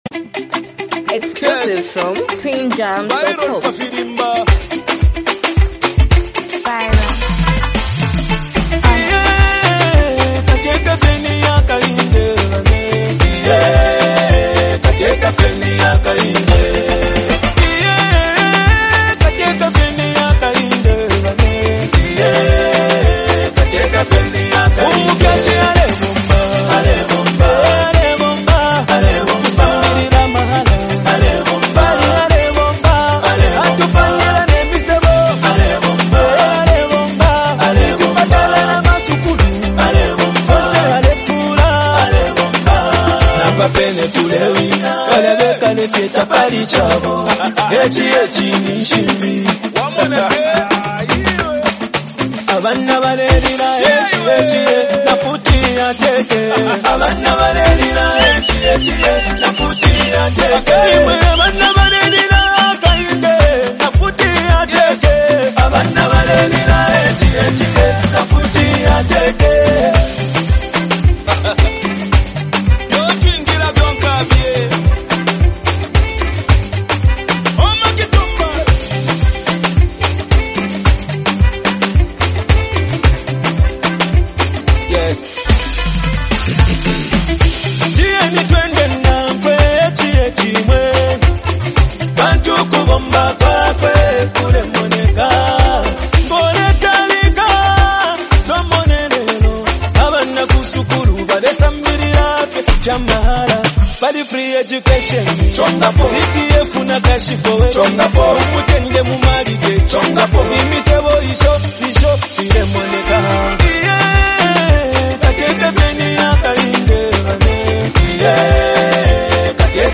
socially conscious and emotionally charged song